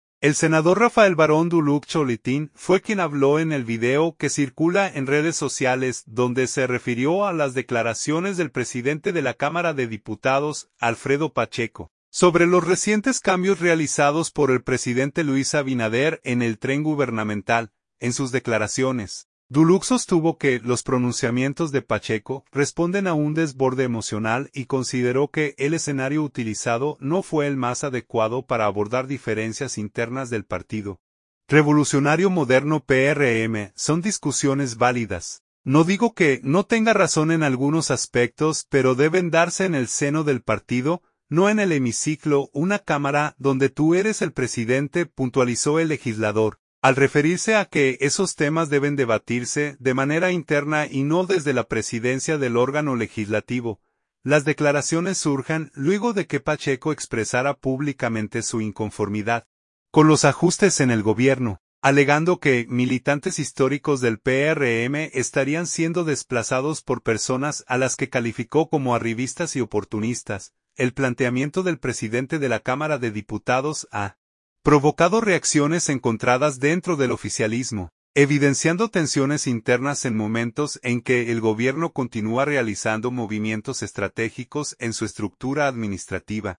Santo Domingo. – El senador Rafael Barón Duluc (Cholitín) fue quien habló en el video que circula en redes sociales, donde se refirió a las declaraciones del presidente de la Cámara de Diputados, Alfredo Pacheco, sobre los recientes cambios realizados por el presidente Luis Abinader en el tren gubernamental.